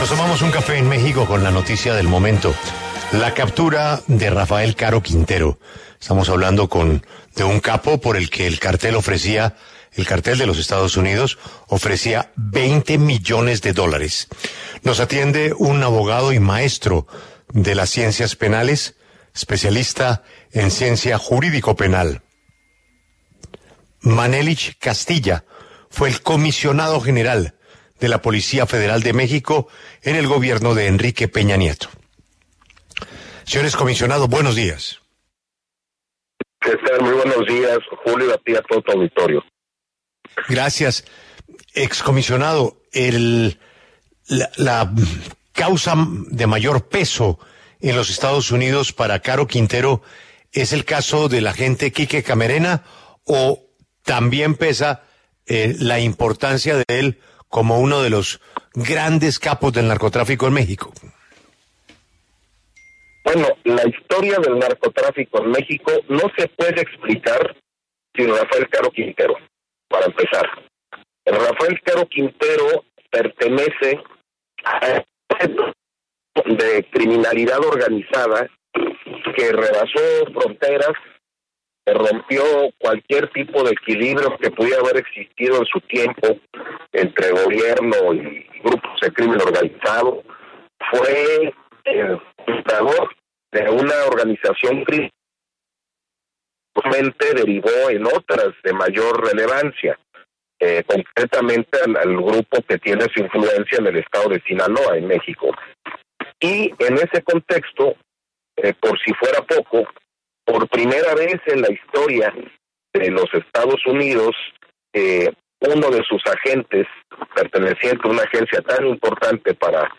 Manelich Castilla, excomisionado general de la Policía Federal de México, abogado y maestro en ciencias penales, habló en La W a propósito de la captura de Rafael Caro Quintero y la situación de las organizaciones criminales en ese país.